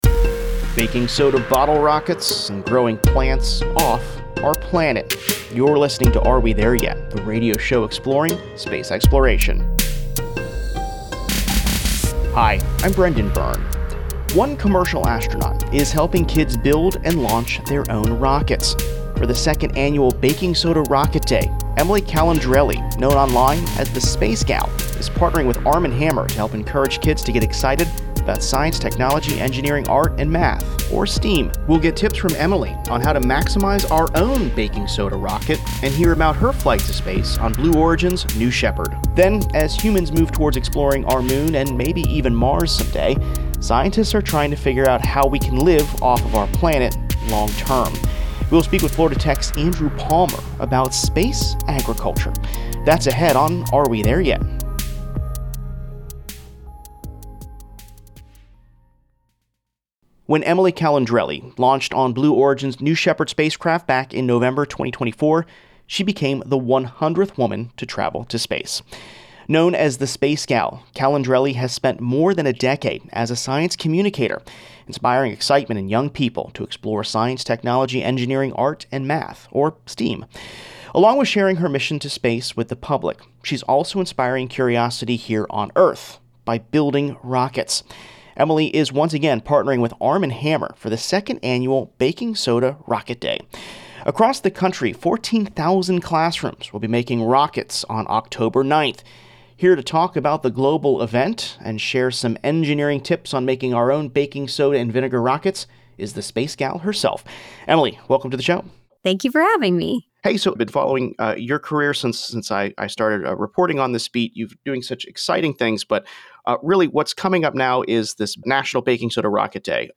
From efforts to launch humans into deep space, to the probes exploring our solar system, "Are We There Yet?" brings you the latest in news from the space beat. Listen to interviews with astronauts, engineers and visionaries as humanity takes its next giant leap exploring our universe.